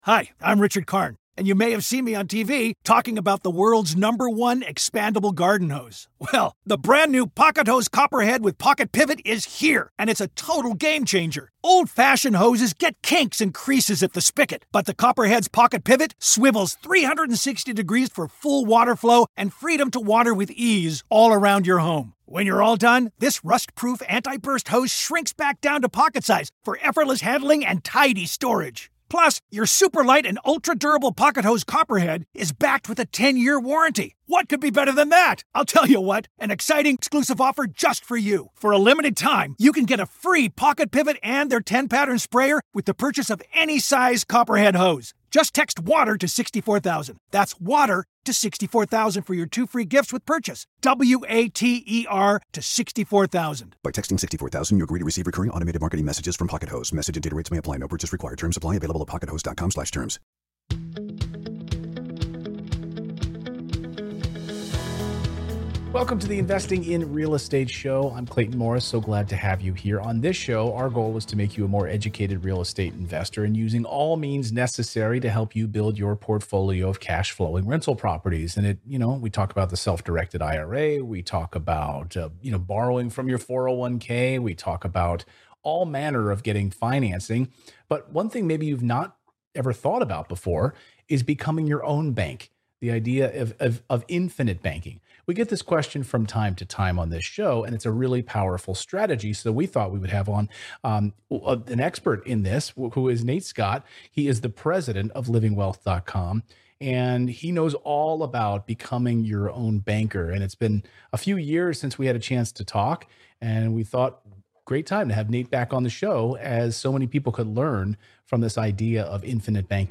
That's why I'm bringing on an expert to break down the main concepts of infinite banking, and help you determine if it's a fit for you.